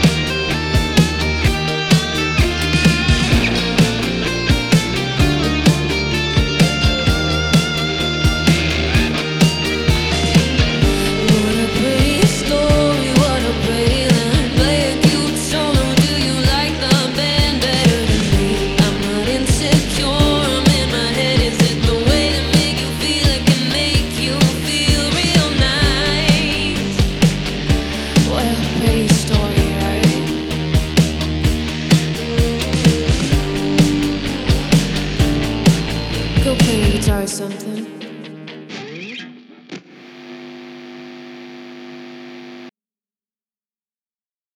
• Pop
• Singer/songwriter
Vokal